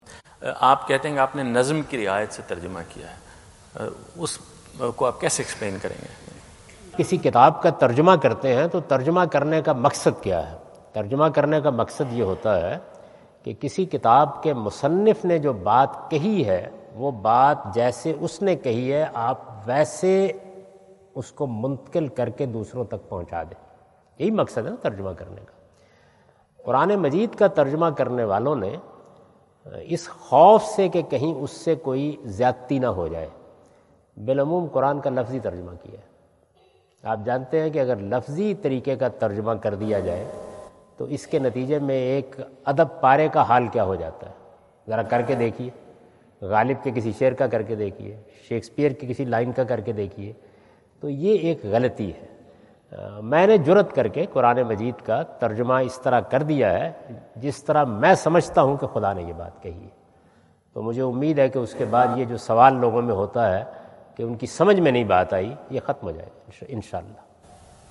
Javed Ahmad Ghamidi answer the question about "Please explain Nazm e Quran?" During his US visit at Wentz Concert Hall, Chicago on September 23,2017.